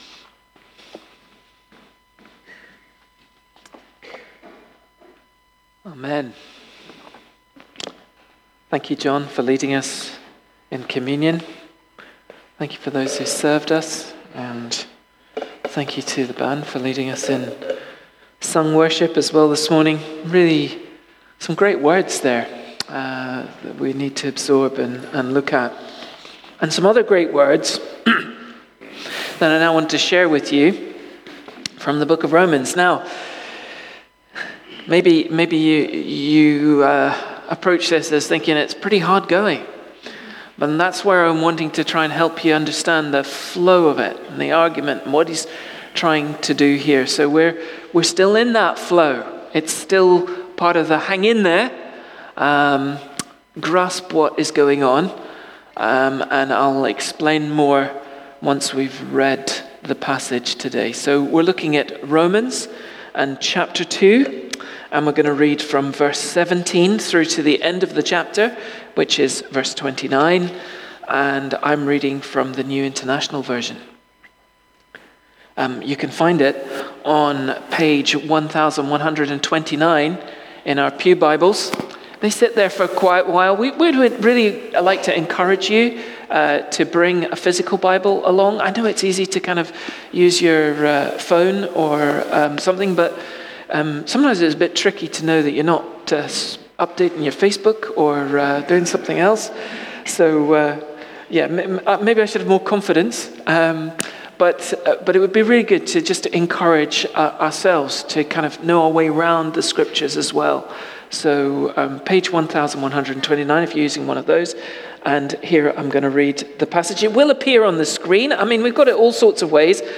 Bridge of Don Baptist Church Sermons